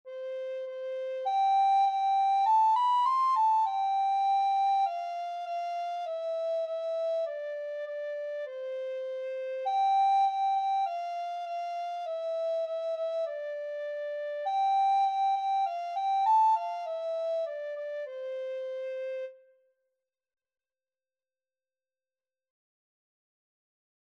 Traditional Trad. Baa, Baa Black Sheep Soprano (Descant) Recorder version
C major (Sounding Pitch) (View more C major Music for Recorder )
Moderato
4/4 (View more 4/4 Music)
C6-C7
Traditional (View more Traditional Recorder Music)
Baa_Baa_Black_Sheep_REC.mp3